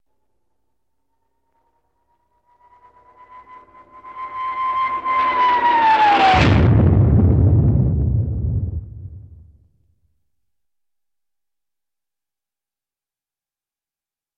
На этой странице собраны звуки авиакатастрофы — от гула падающего самолета до тревожных сигналов и хаотичных шумов.
Звук падающего самолета при авиакатастрофе